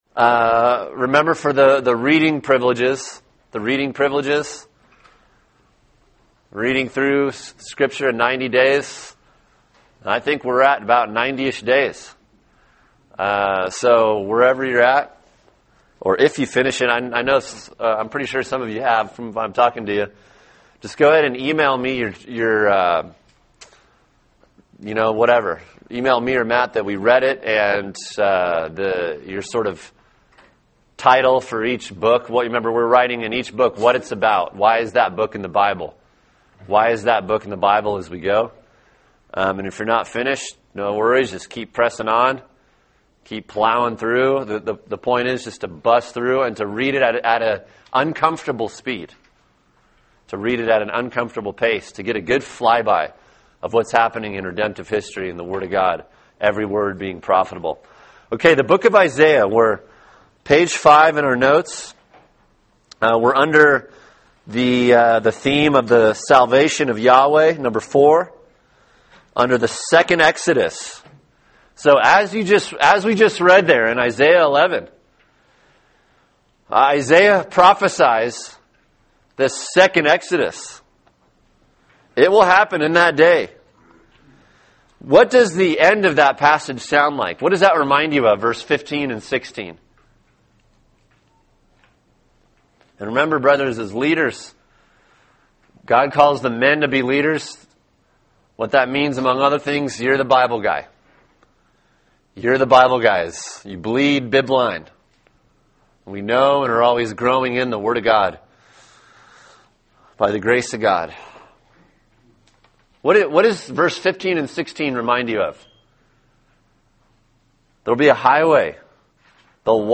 Old Testament Survey Lesson 14: Isaiah, Jeremiah